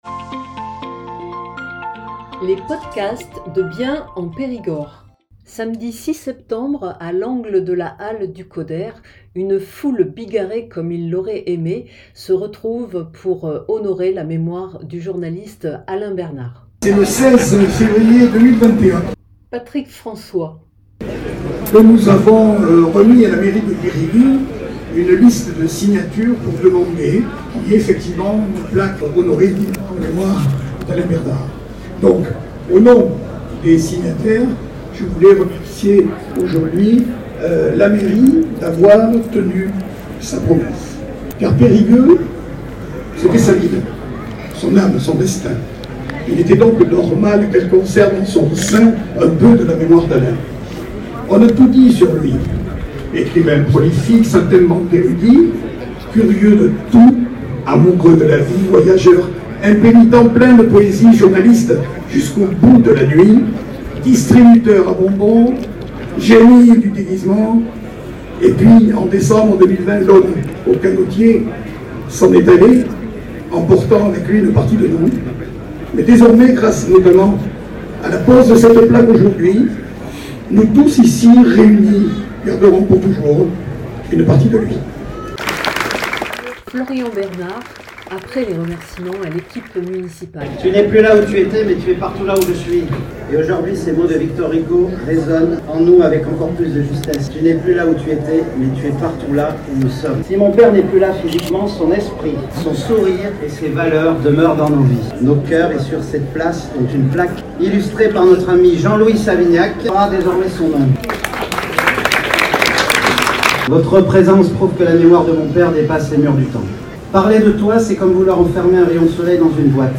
• Ceux qui n’ont pu assister à la cérémonie peuvent écouter ici l’essentiel des prises de parole